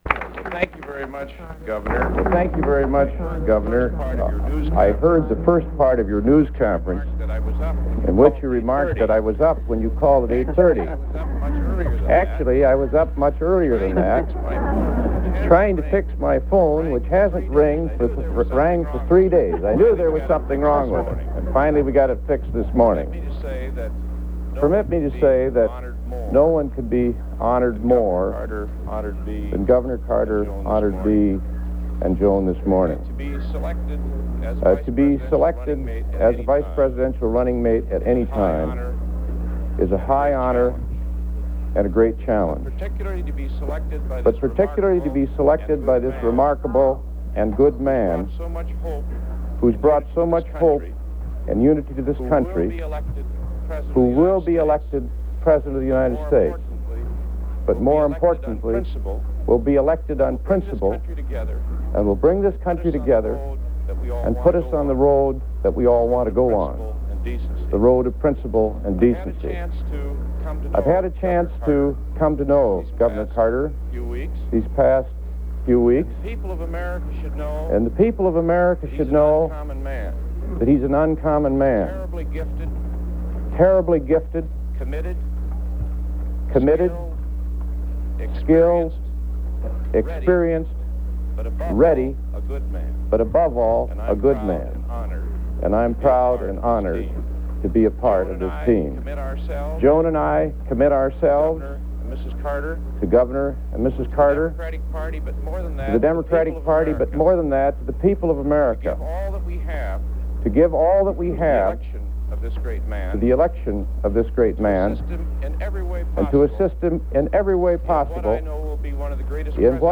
U.S. vice presidential candidate Walter Mondale speaks about the duties and responsibilities of the vice president
Broadcast 1975 July 15.